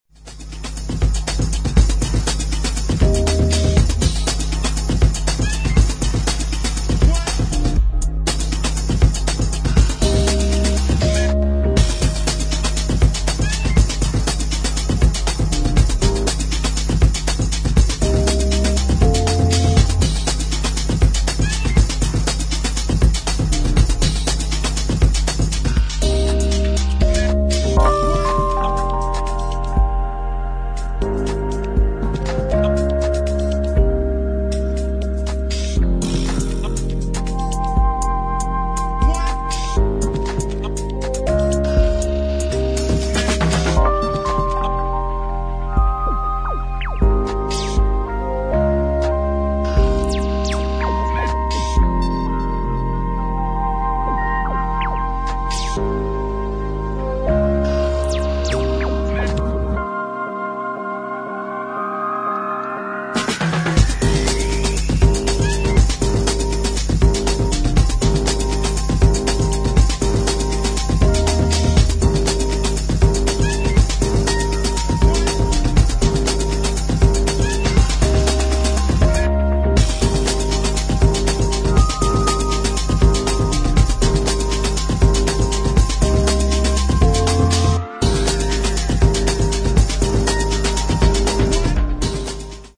[ HOUSE / BROKEN BEAT ]
(Instrumental)